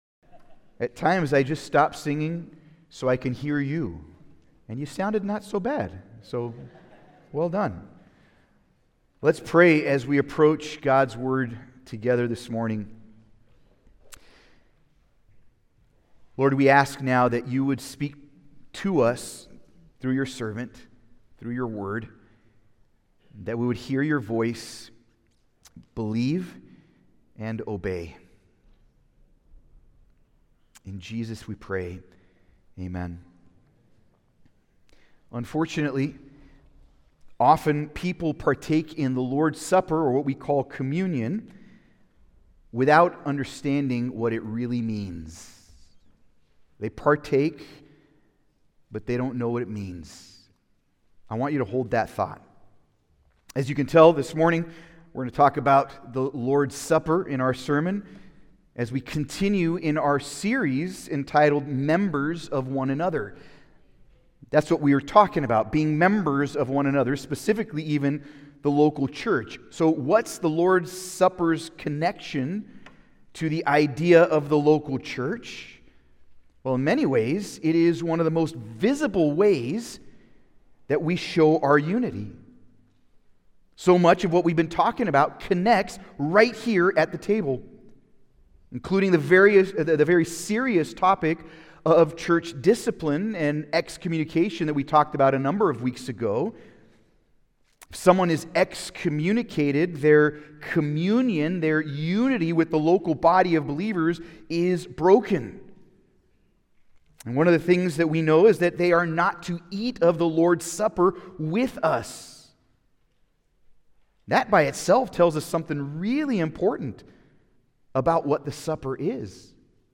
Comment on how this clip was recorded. Passage: 1 Corinthians 11:17-34 Service Type: Sunday Service